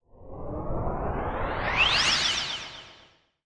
Quick Rising Sound.wav